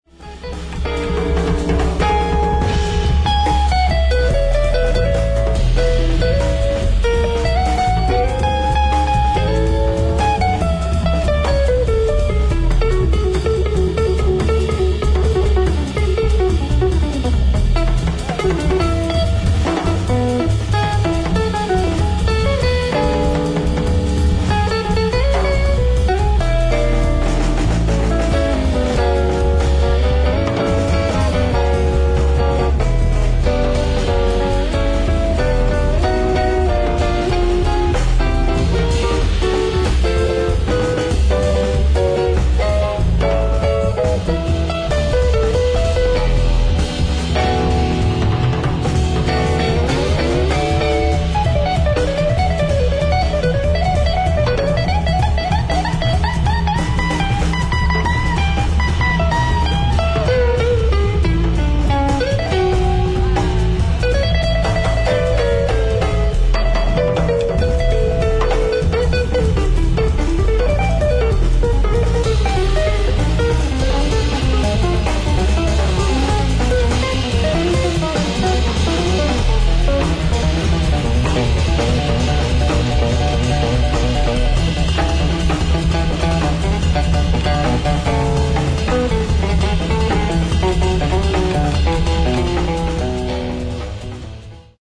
ライブ・アット・マルシアック・ジャズフェスティバル、フランス
※試聴用に実際より音質を落としています。